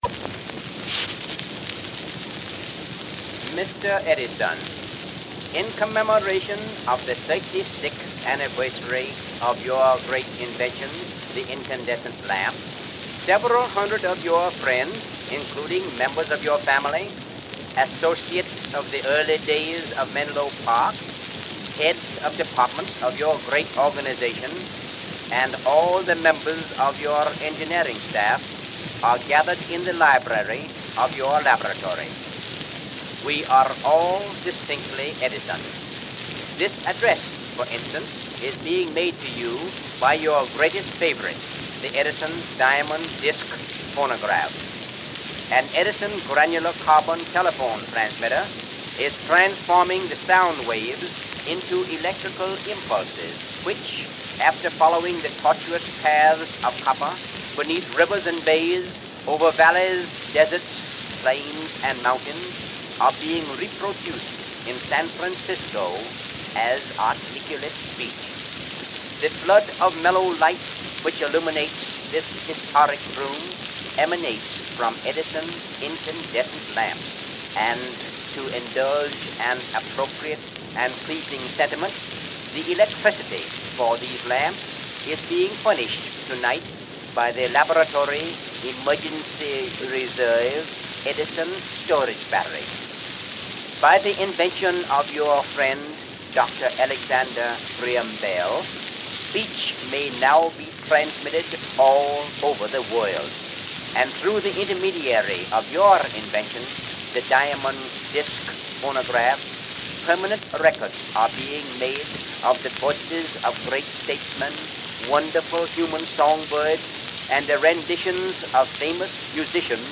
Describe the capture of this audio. Subjects Phonograph Telephone--Transmitters and transmission Material Type Sound recordings Language English Extent 00:08:05 Venue Note Recorded on Edison Diamond Disc celluloid sub-master, Oct. 17, 1915.